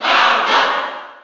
Falco_Cheer_NTSC_SSB4.ogg.mp3